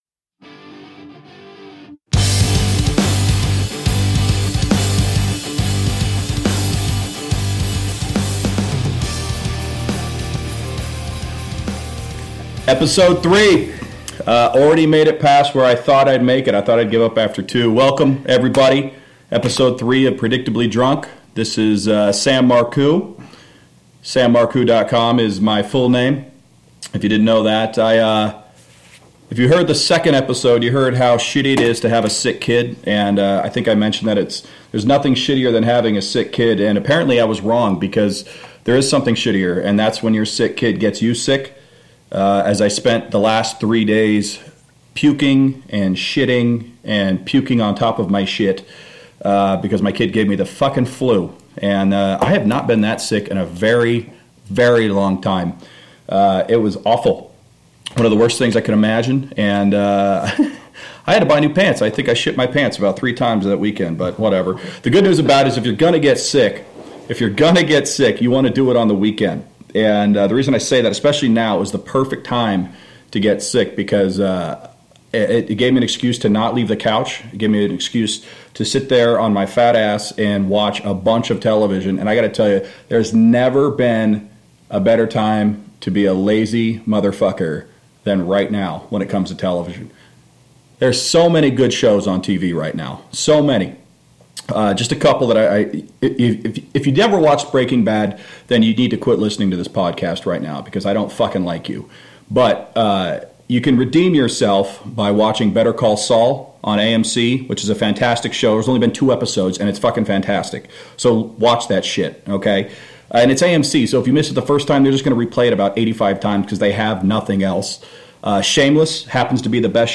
They also discuss sports, how they met, and attempt to try and set a record for the loudest laugh ever recorded.
Two smart people talking about stupid things.